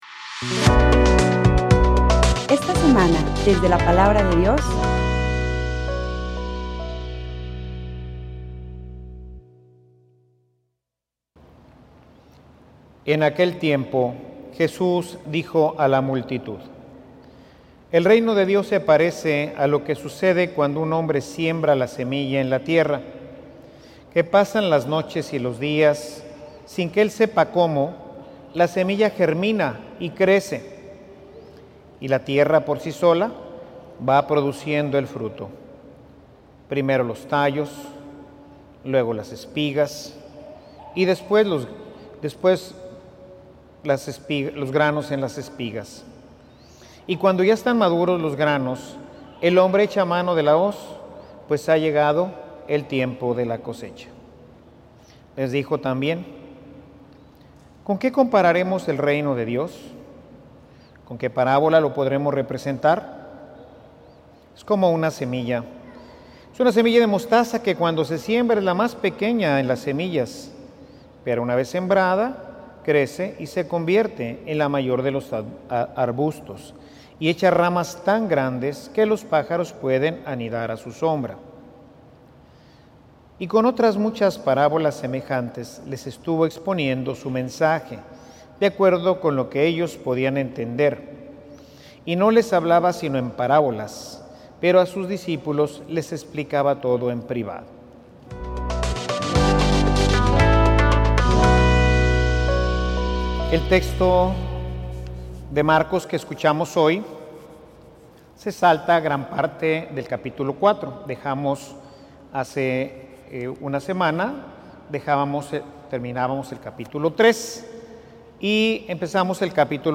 Homilia_Eppur_si_muove.mp3